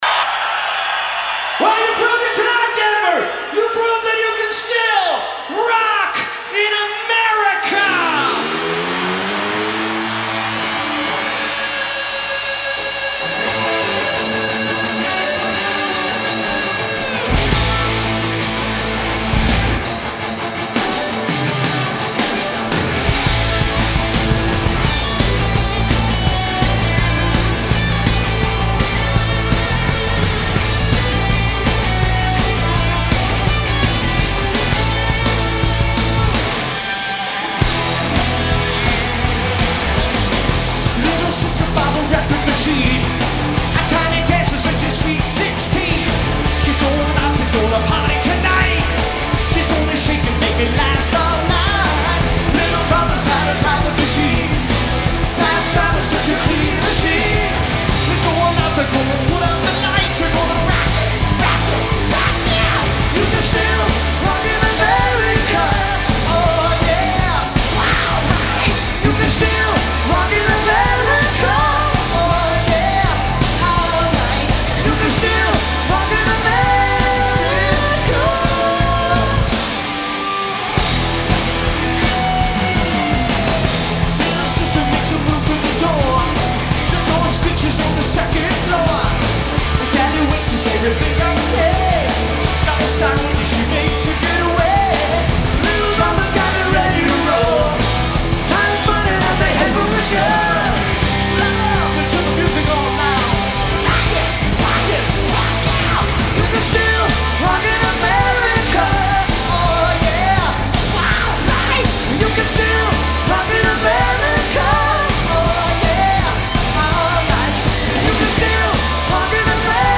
performed in concert by the Nineties’ first supergroup